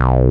Bass (SMUCKERS).wav